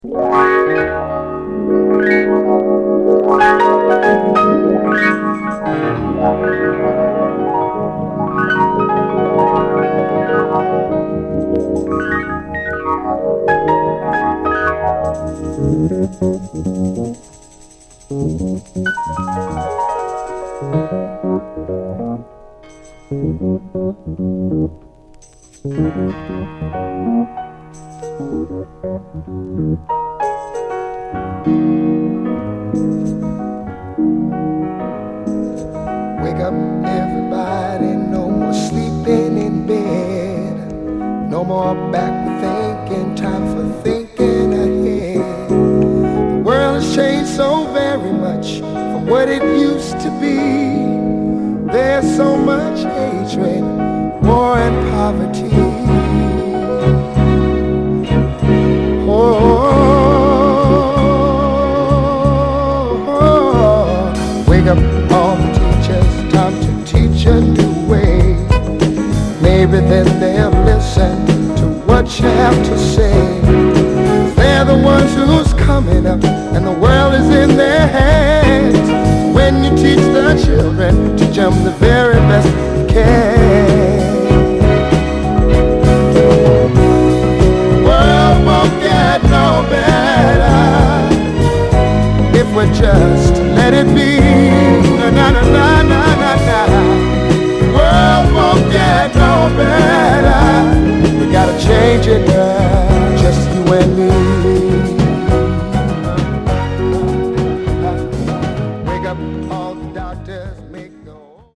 心に染みる優しいメロディと力強いメッセージ性は、現代においても色褪せずになお輝き続けるエヴァーグリーンな名曲ですね。
※試聴音源は実際にお送りする商品から録音したものです※